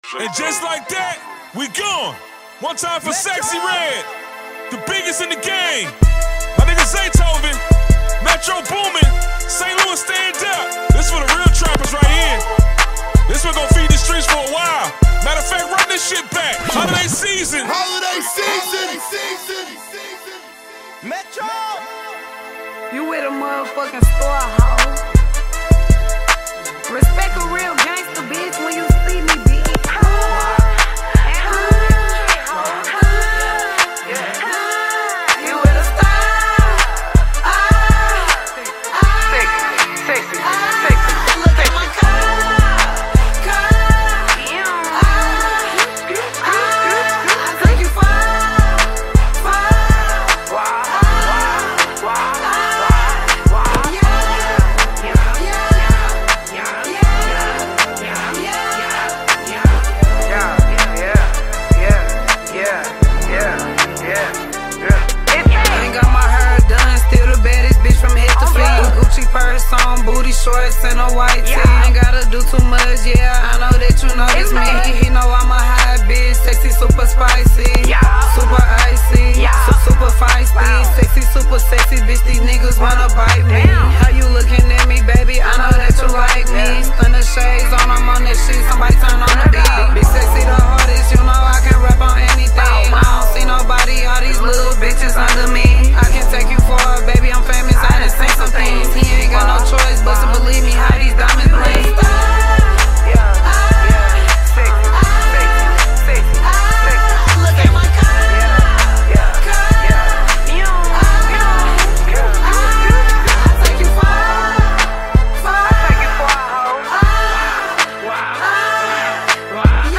It delivers a sound that feels both fresh and consistent.